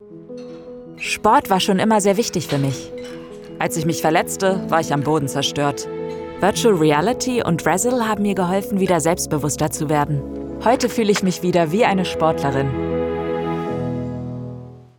Microphone: Rode NT2-A Interface: Volt 1 Sound booth: takustic
Sprechprobe: Industrie (Muttersprache):
I work from a fully equipped home studio, ensuring high-quality audio and quick turnaround times. My voice is versatile and adaptable, and I am reliable and flexible.